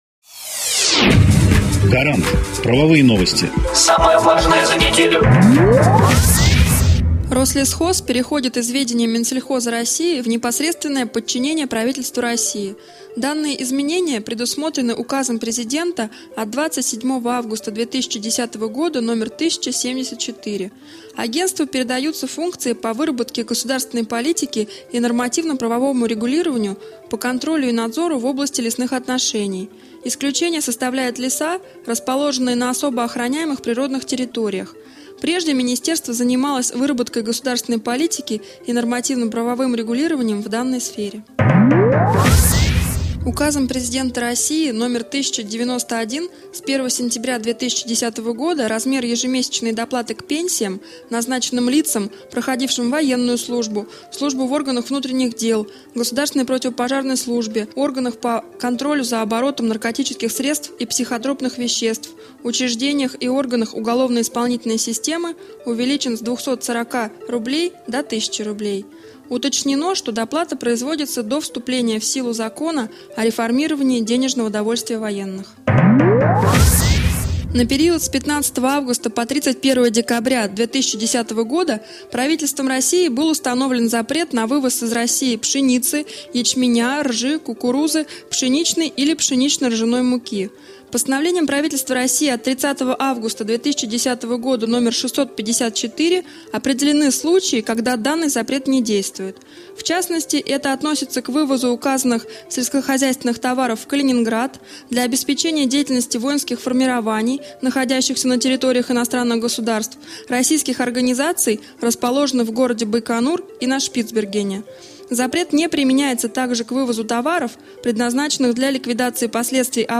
Аудионовости законодательства
Эксперты компании "Гарант" доступно и кратко рассказывают об актуальных законодательных нововведениях за последнюю неделю, акцентируя внимание на самом важном и интересном.